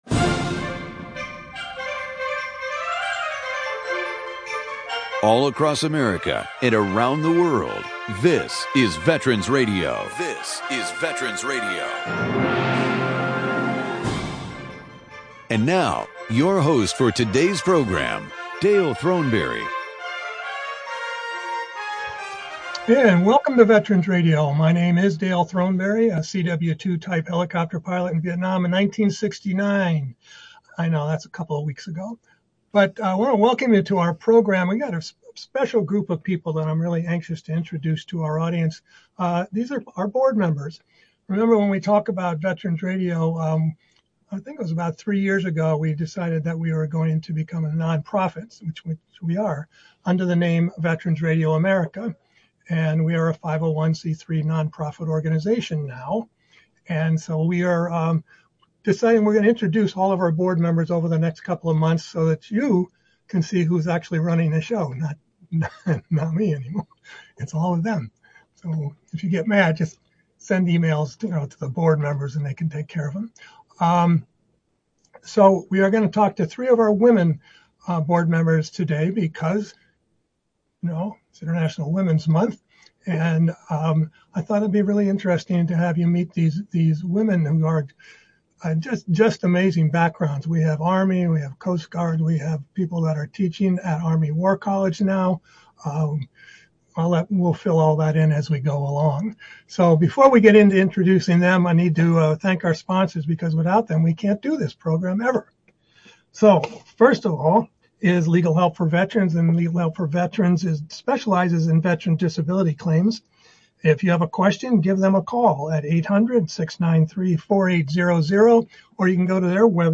2024 Women's History Month Panel